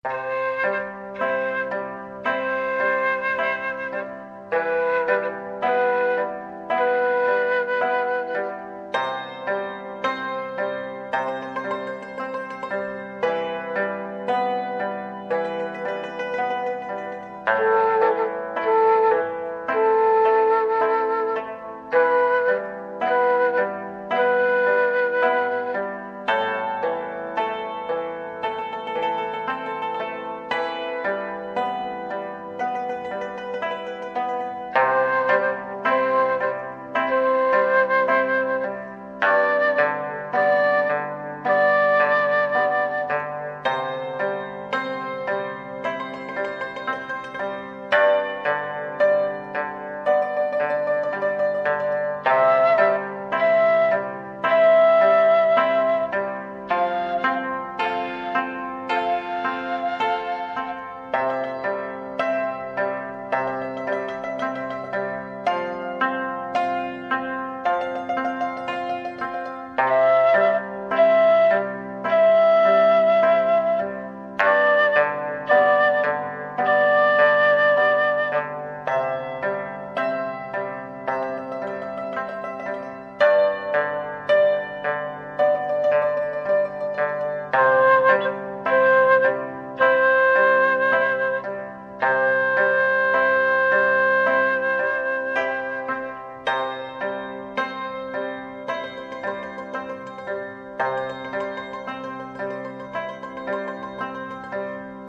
The instructional book and CDs in your program contain lessons led by an accomplished tutor.
Dizi Series 1
practice1.mp3